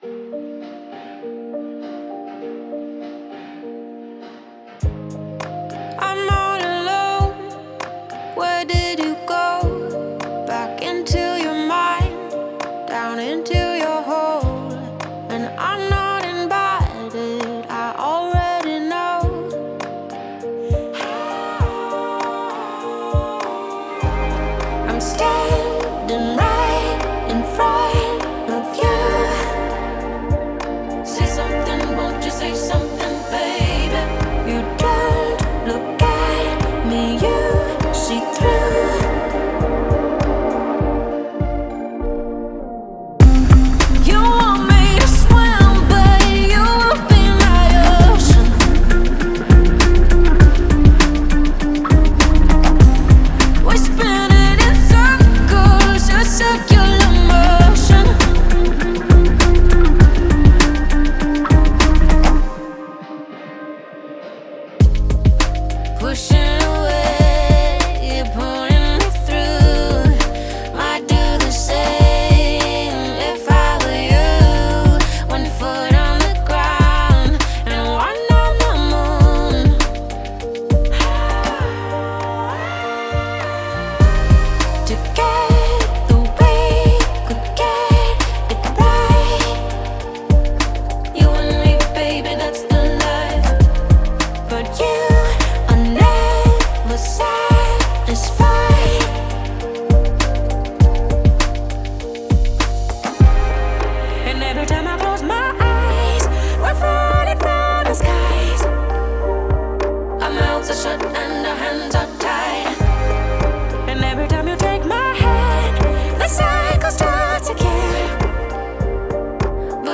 электронная композиция